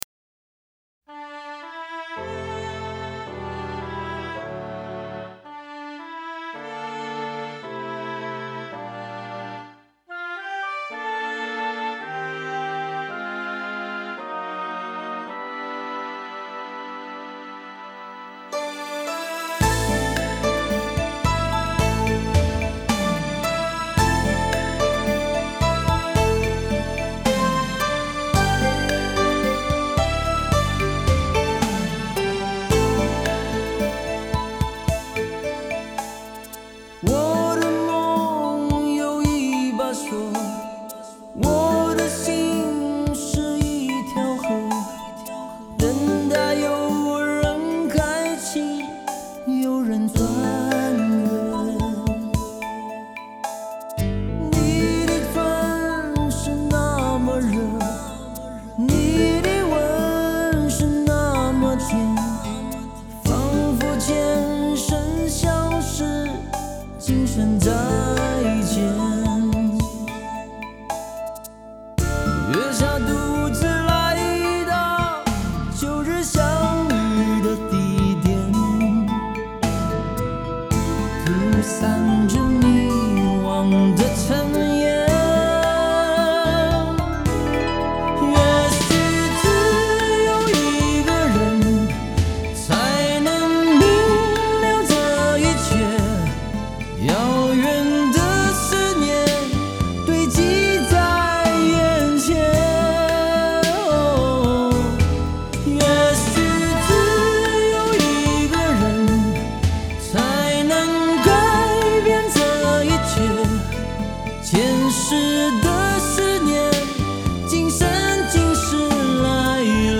类别: 国风